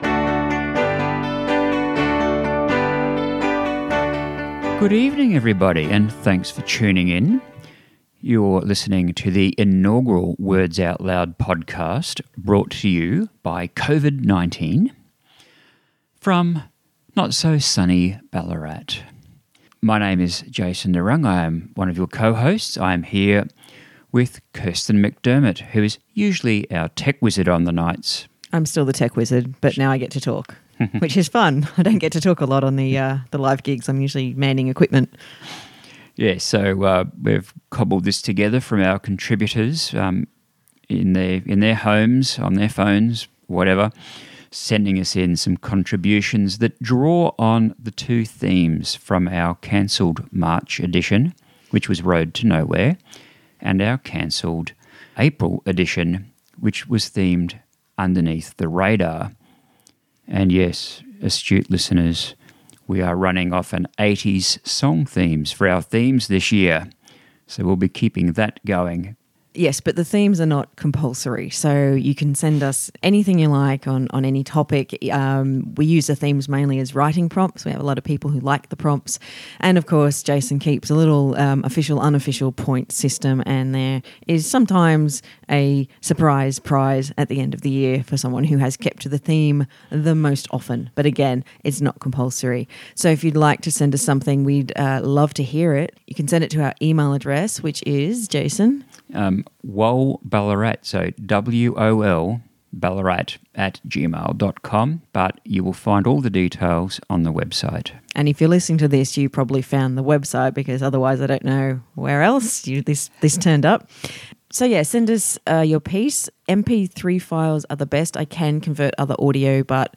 Some of the works here draw on the themes of the cancelled March and April live events, but our themes are never compulsory, and it’s no surprise to find the pandemic being mentioned in several of the works. This podcast also includes a rare song for a Words Out Loud event, where the focus is on spoken word.